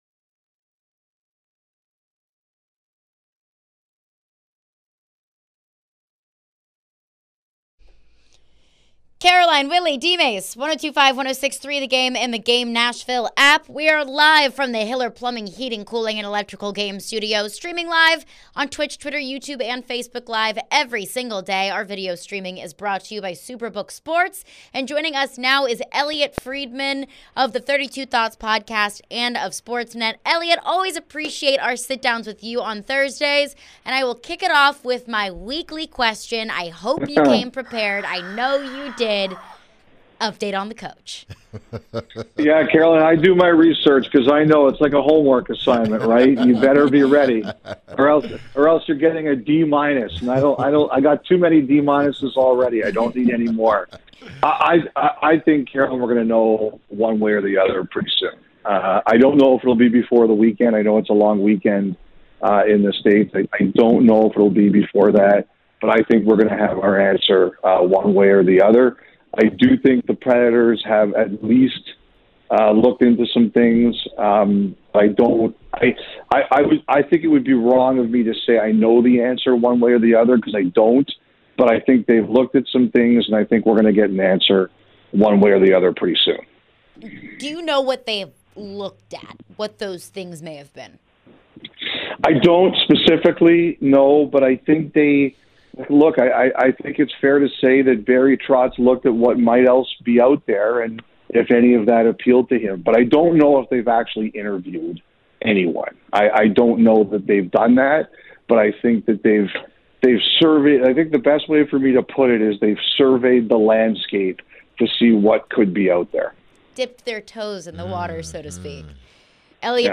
Elliotte Friedman Interview (5-25-23)